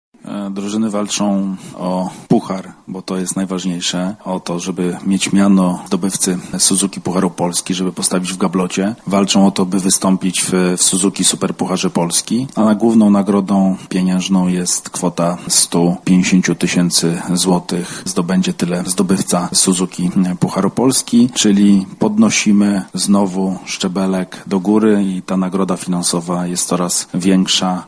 Prezes PZKosz mówi o tym, jakie nagrody oprócz samego trofeum czekają na zwycięzcę Pucharu.